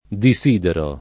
diS&dero]: disiderare alcuna cosa la quale noi non dovemo volere [